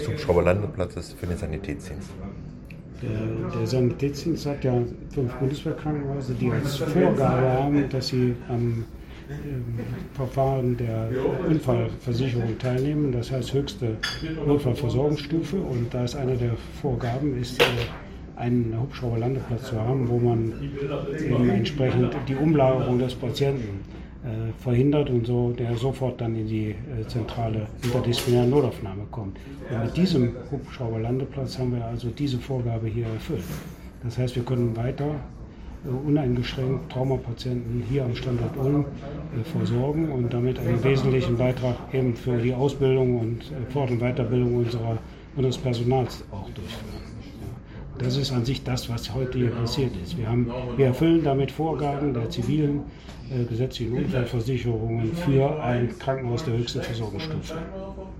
Der Stellvertreter des Inspekteurs des Sanitätsdienstes der Bundeswehr und Kommandeur Gesundheitseinrichtungen, Generalstabsarzt Dr. Stephan Schoeps anlässlich der Einweihung des Hubschrauberlandeplatzes auf dem Dach des Bundeswehrkrankenhauses Ulm.